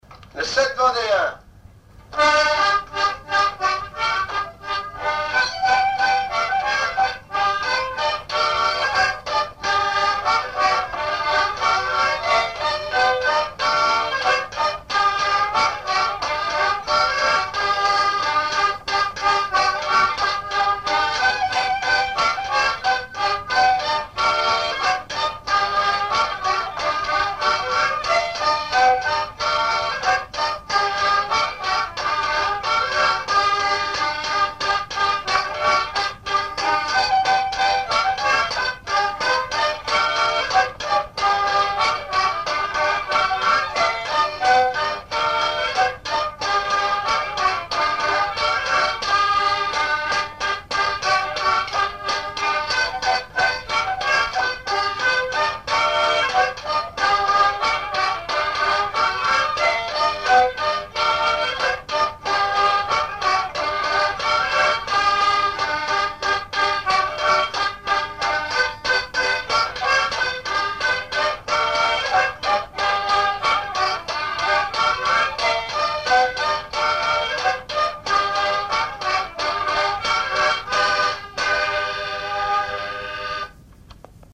danse : scottich sept pas
enregistrements du Répertoire du violoneux
Pièce musicale inédite